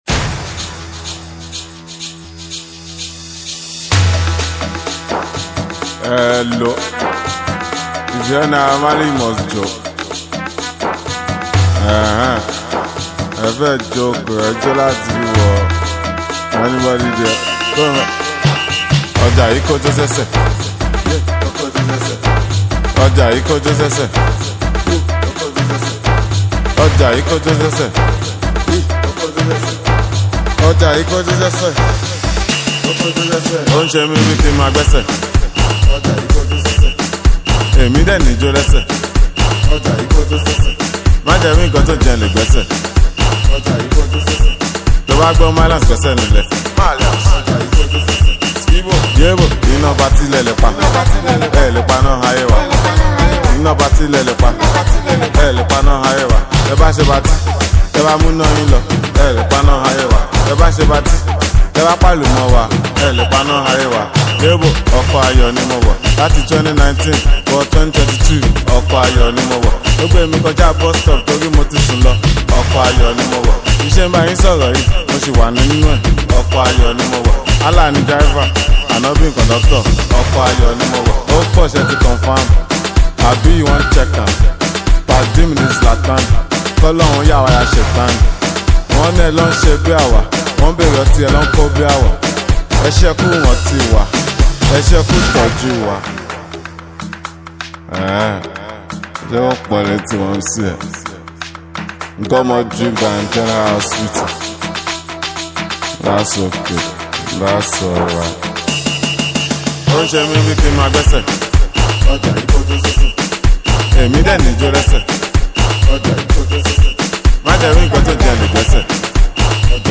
a catchy record